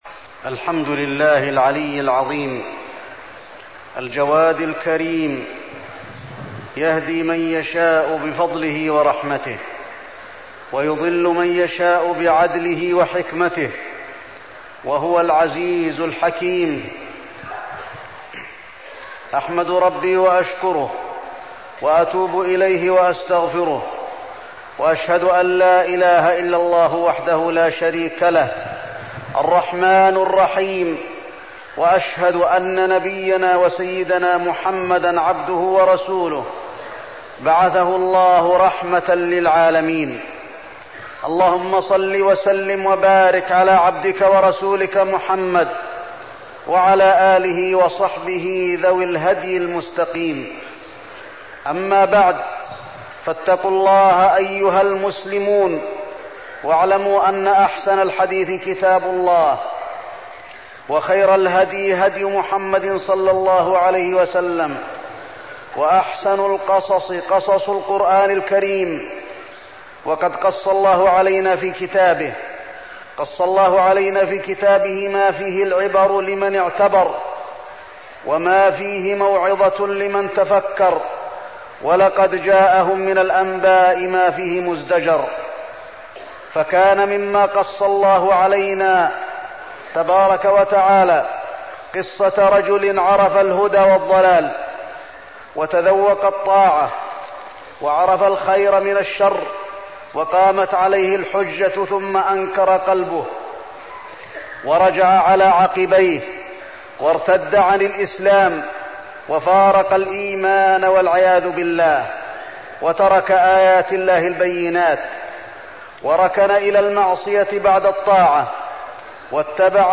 تاريخ النشر ٩ شوال ١٤١٥ هـ المكان: المسجد النبوي الشيخ: فضيلة الشيخ د. علي بن عبدالرحمن الحذيفي فضيلة الشيخ د. علي بن عبدالرحمن الحذيفي من قصص القرآن عزير